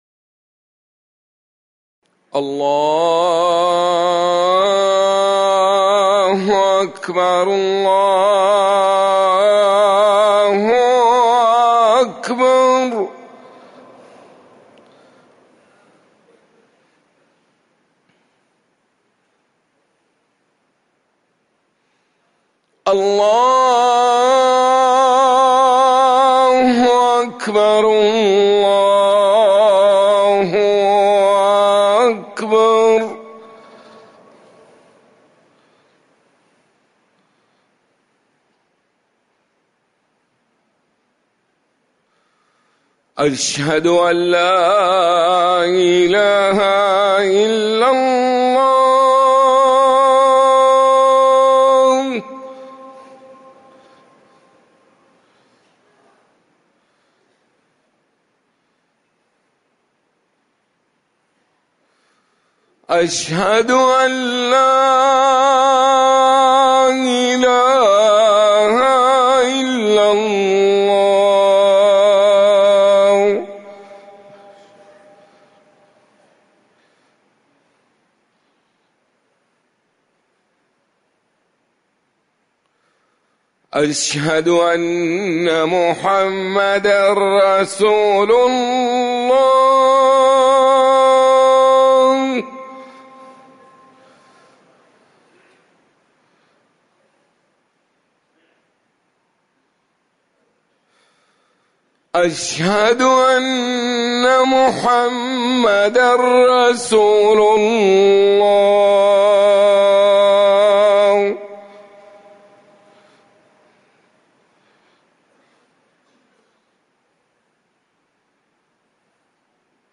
أذان الفجر الثاني
تاريخ النشر ٢٢ صفر ١٤٤١ هـ المكان: المسجد النبوي الشيخ